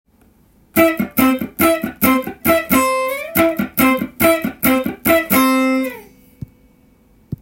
オクターブ奏法でAmペンタトニックスケールを使用した
譜面通り弾いてみました
TAB譜では３連符のフレーズを集めてみました。